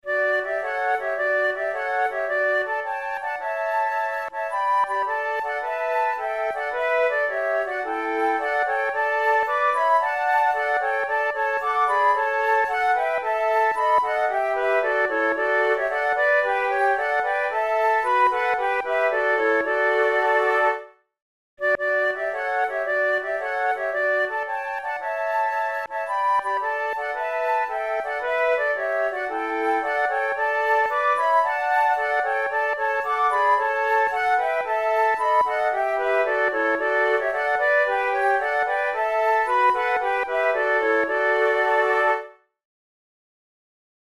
Traditional English Christmas carol, arranged for flute trio
This carol is a bit unusual in that its verses are sung in 6/8 time, while the chorus switches to 2/2, but always keeping the same tempo.